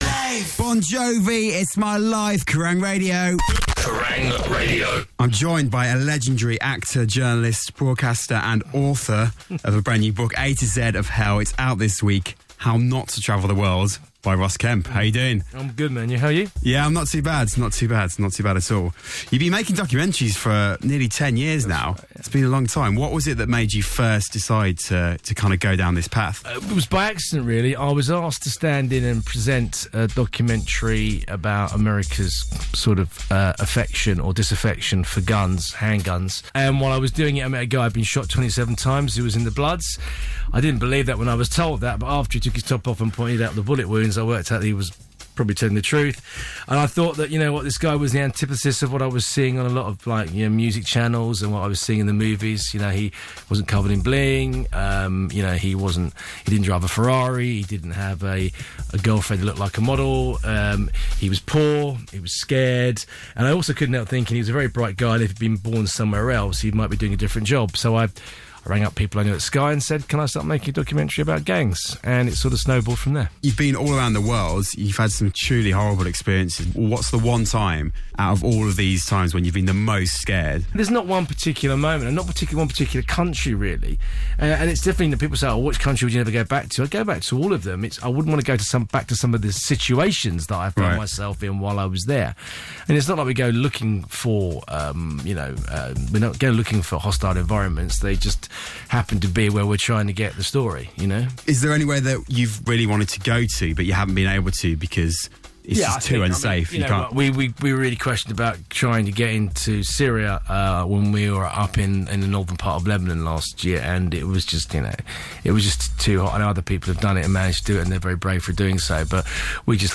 interview with Ross Kemp here!